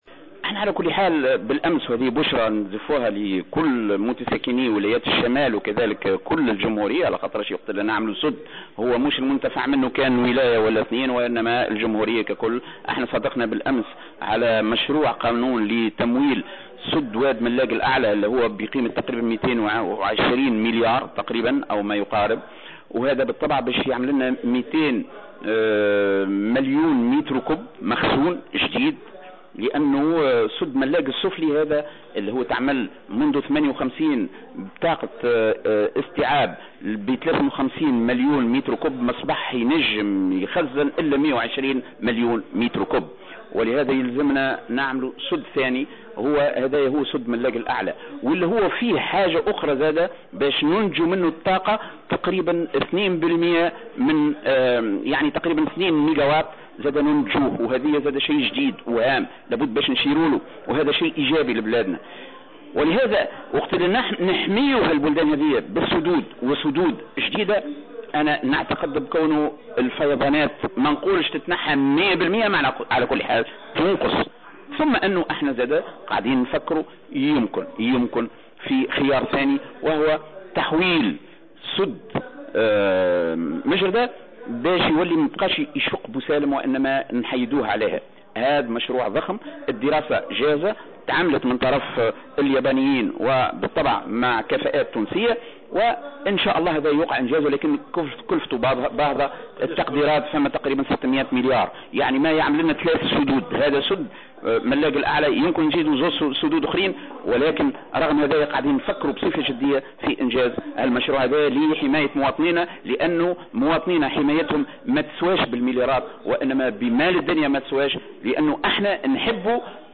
وقال براهم في تصريح لمراسل الجوهرة أف أم بالمهدية اليوم الثلاثاء، إن المجلس يدرس خيارا آخر لحماية بوسالم من الفيضانات يتمثل في تغيير مسار وادي مجردة رغم ارتفاع كلفته التي قد تبلغ 600 مليارا من المليمات.